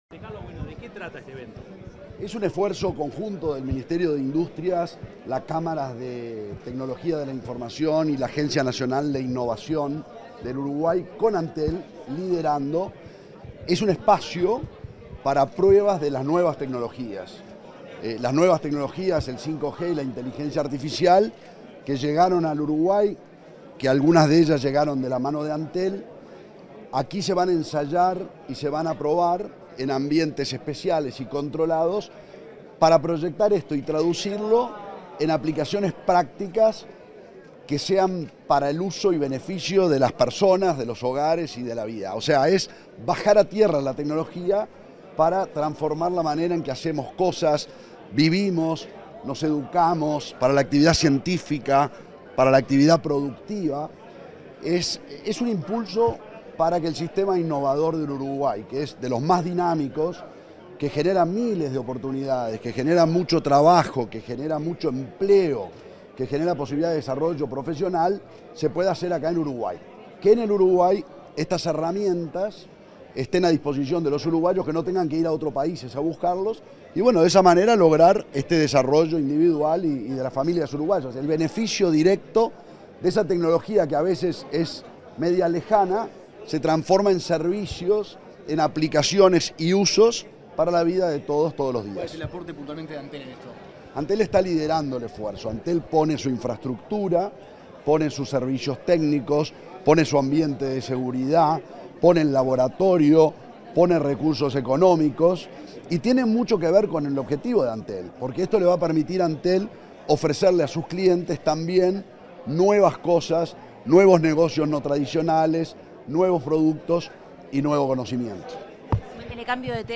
Declaraciones del presidente de Antel, Gabriel Gurméndez
Tras la presentación de Open Digital Lab, este 17 de octubre, el presidente de Antel, Gabriel Gurméndez, realizó declaraciones a la prensa.